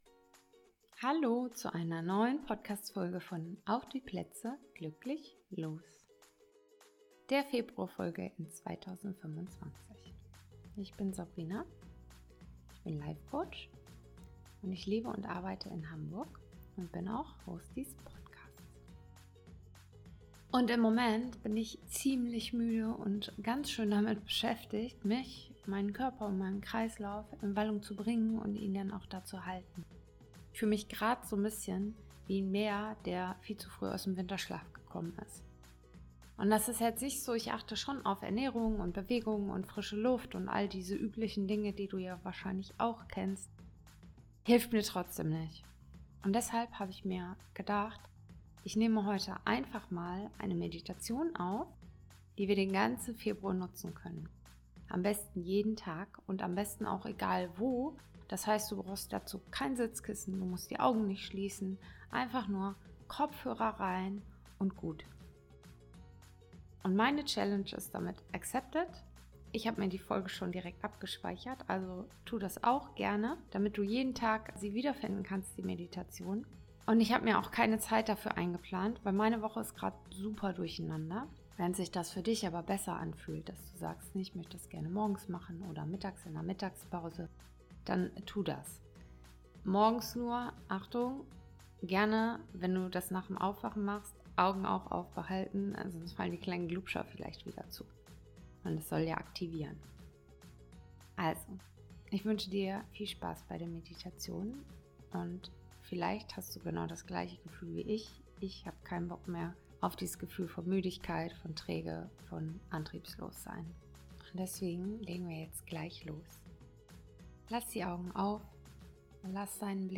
- Meditation | Aktiviere deine Energie